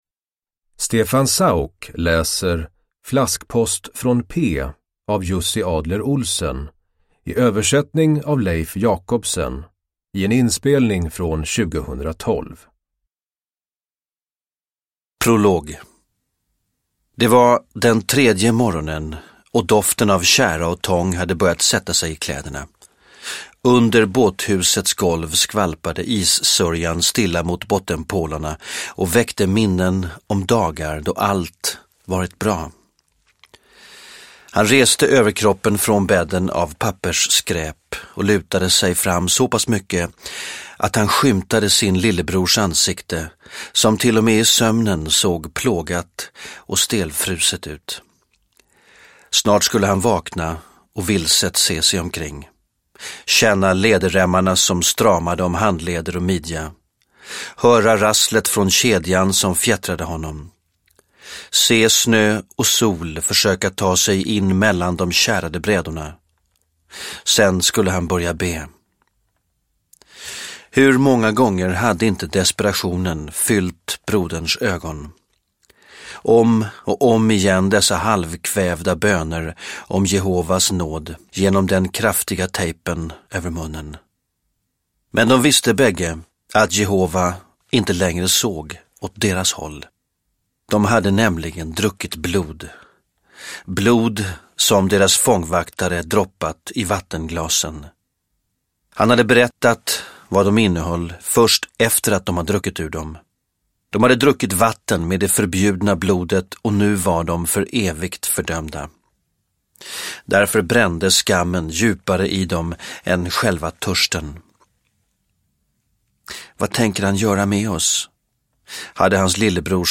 Flaskpost från P – Ljudbok – Laddas ner
Uppläsare: Stefan Sauk